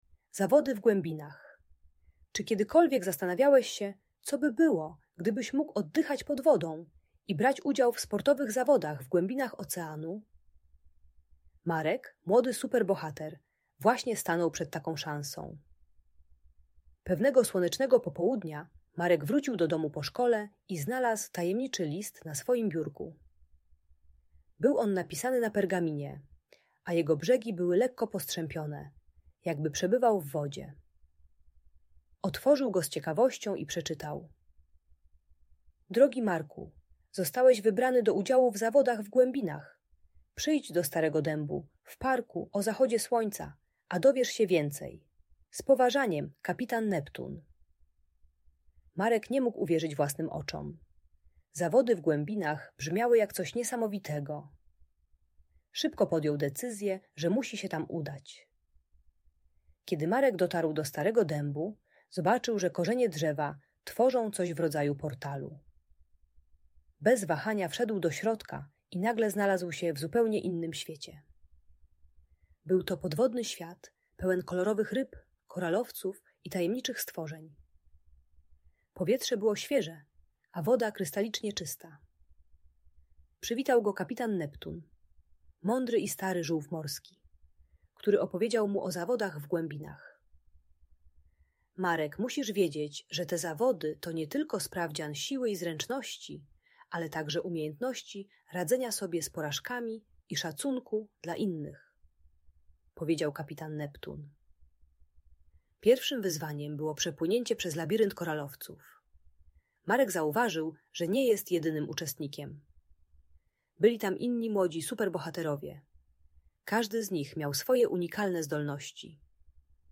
Zawody w Głębinach - Bunt i wybuchy złości | Audiobajka